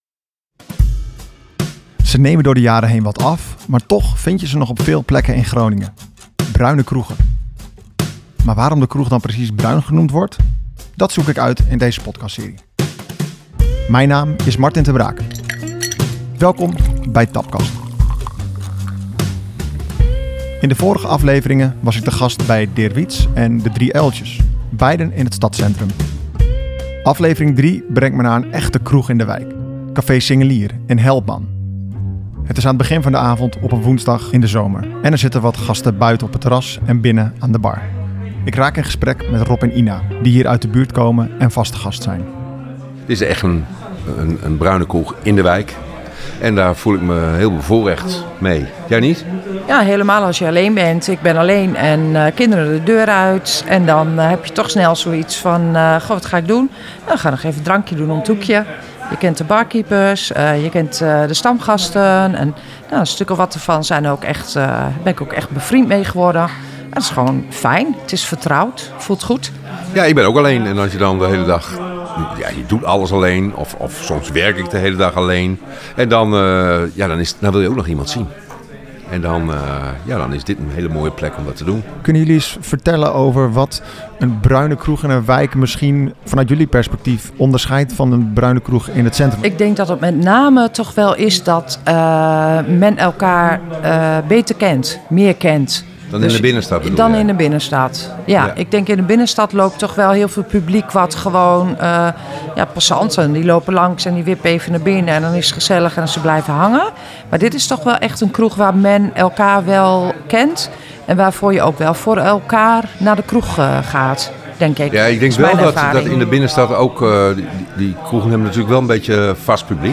In deze de aflevering zijn we te gast in Café Singelier. Een echte buurtkroeg in de Groninger wijk Helpman.